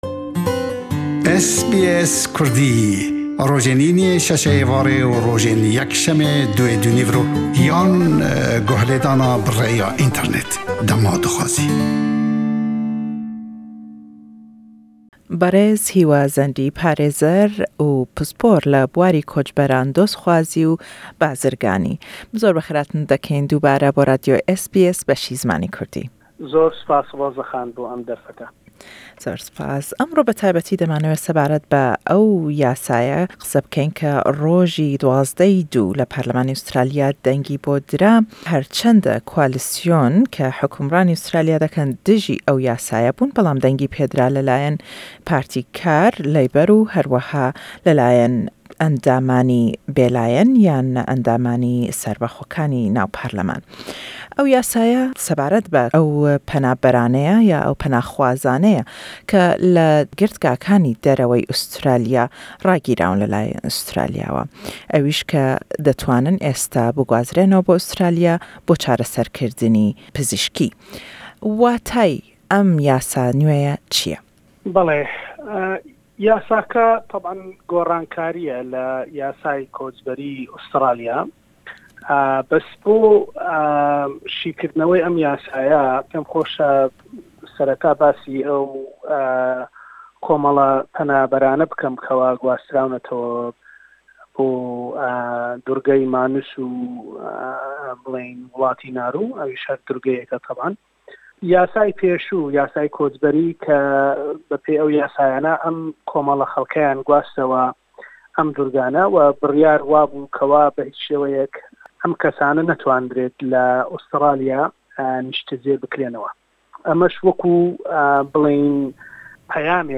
û le em lêdwane da ew yasa nwê ye man bo şî dekatewe.